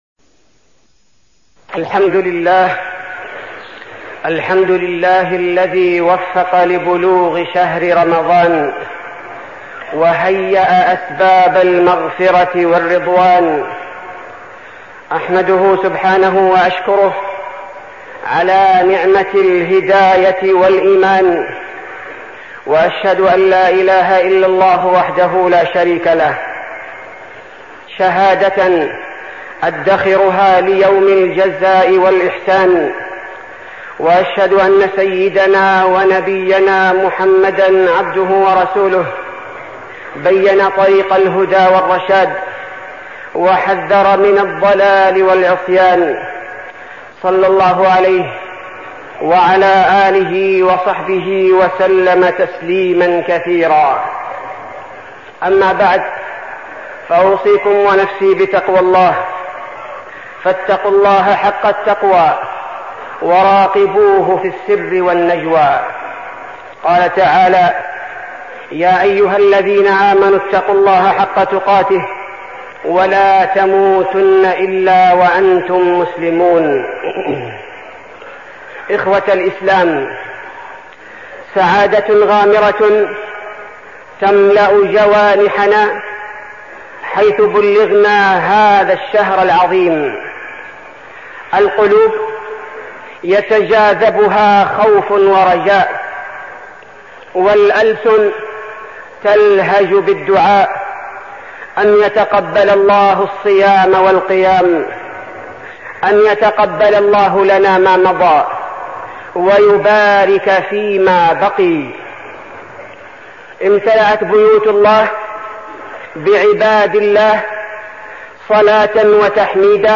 تاريخ النشر ٢٧ رمضان ١٤١٦ هـ المكان: المسجد النبوي الشيخ: فضيلة الشيخ عبدالباري الثبيتي فضيلة الشيخ عبدالباري الثبيتي الأعمال الصالحة